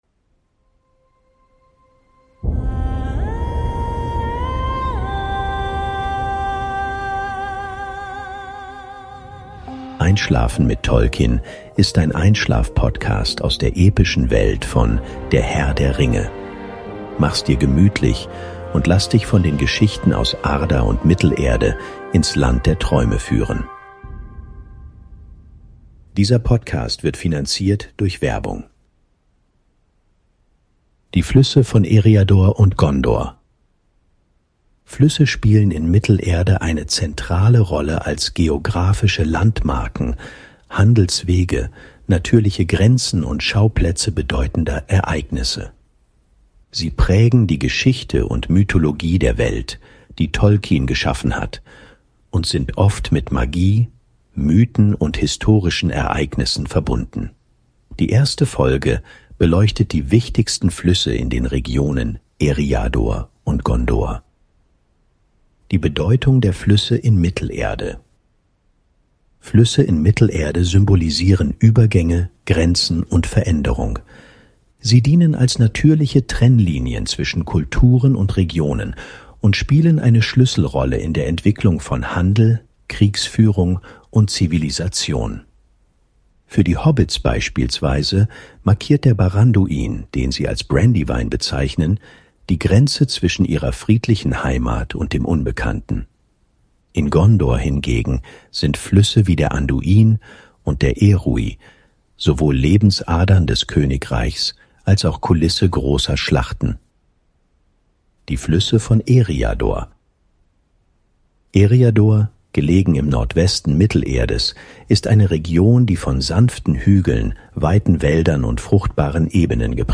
Wir lesen dir was vor!
Der Podcast ist unter der Lizenz CC-BY-SA 4.0 verfügbar und entsteht durch Unterstützung von KI.